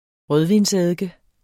Udtale [ ˈʁœðvins- ]